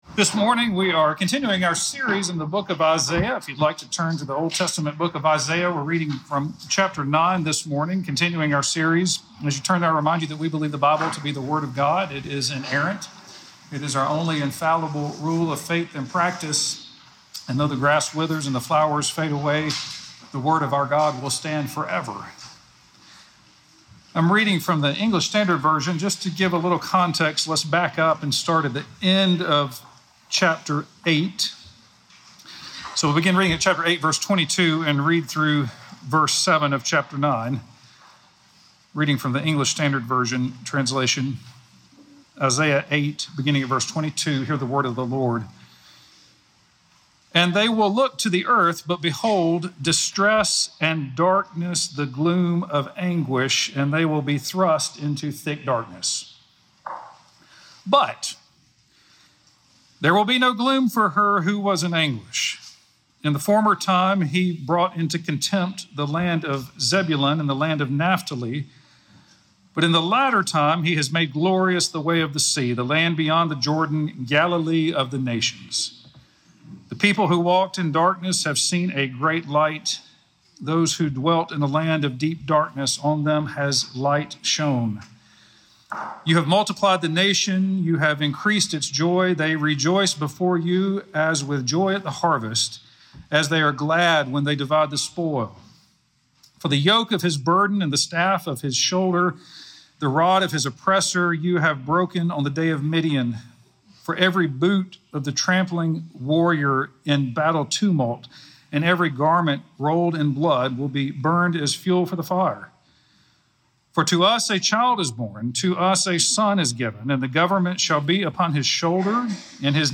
Darkness and Light Sermon